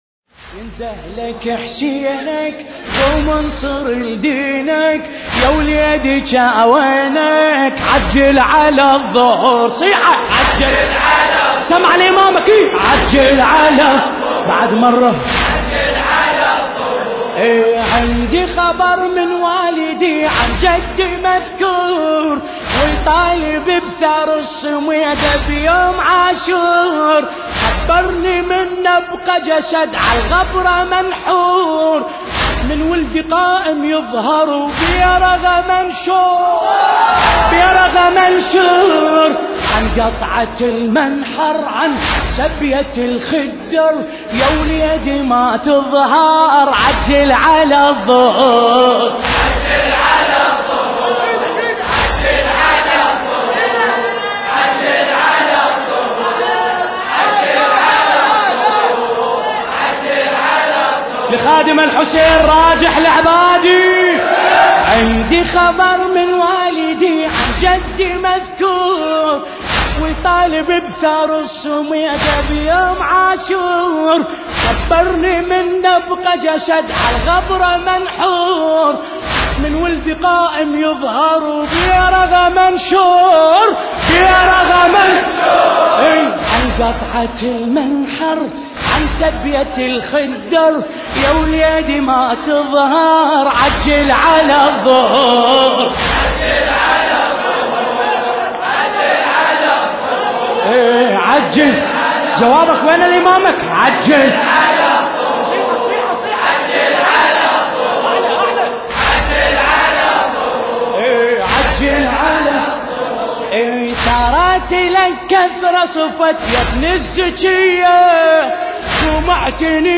الحجم: 2.75 MB الشاعر: راجح العبادي المكان: حسينية الرسول الأعظم (صلى الله عليه وآله وسلم)/ النجف الأشرف – ناحية القادسية التاريخ: محرم الحرام 1440 للهجرة